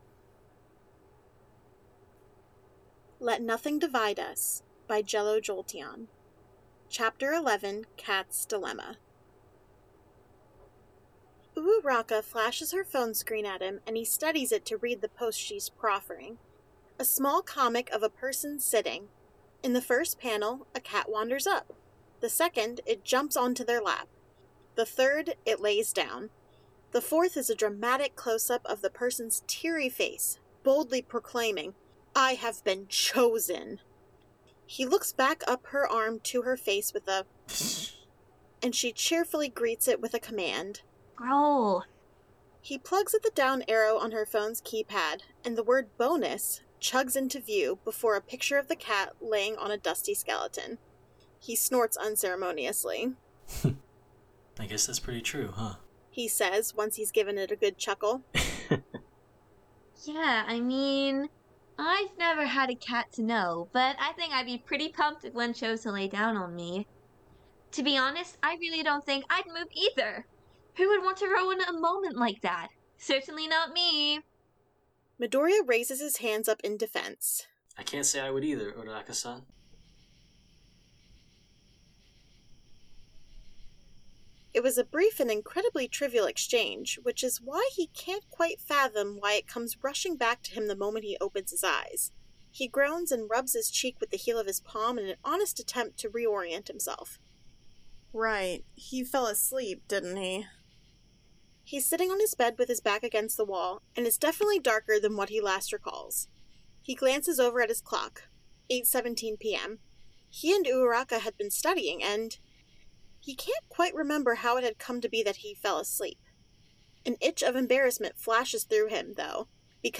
Voice of Healer [OC]
Voice of Ochako Uraraka
Voice of Villain [OC] Voice of Izuku Midoriya
" Stirring in a cup of tea 1 " by Anti-HeroAnnie This sound is licensed under CC BY-NC 4.0 .